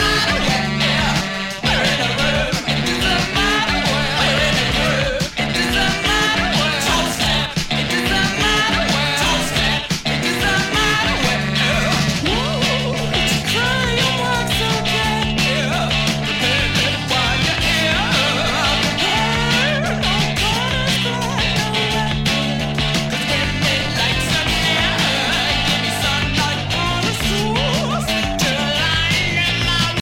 saxofone
Som claramente - diríamos até orgulhosamente - datado